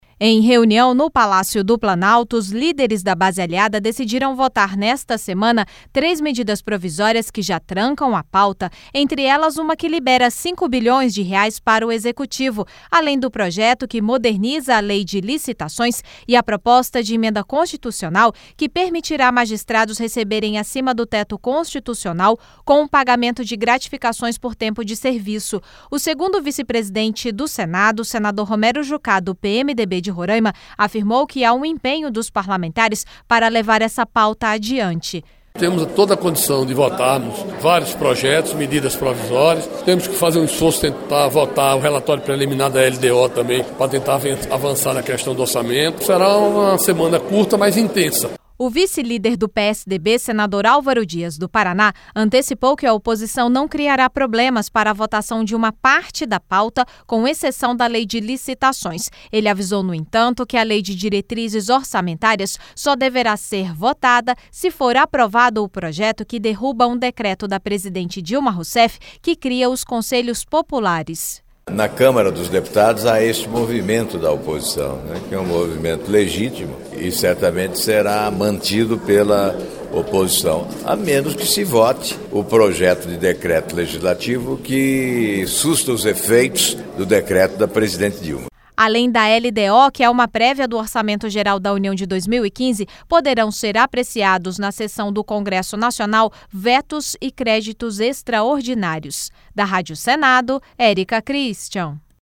LOC: A OPOSIÇÃO CONDICIONOU A APROVAÇÃO DA LDO À DERRUBADA DO DECRETO QUE CRIA CONSELHOS POPULARES. A REPORTAGEM